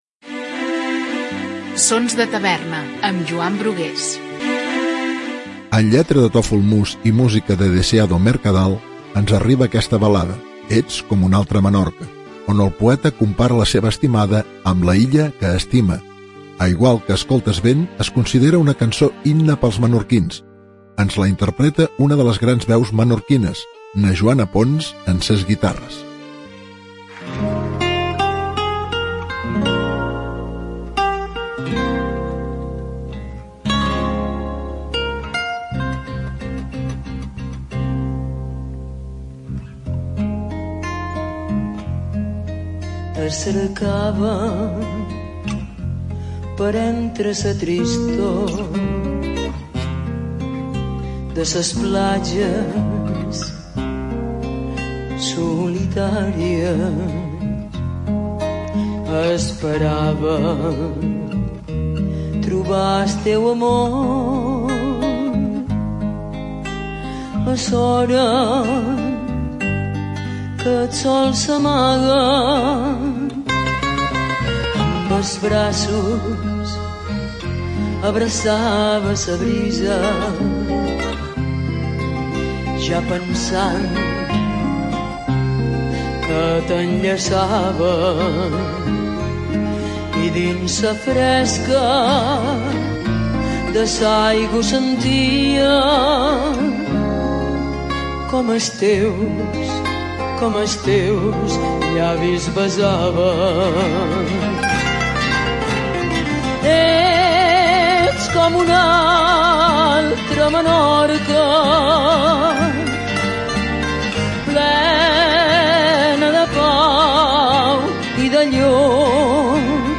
aquesta balada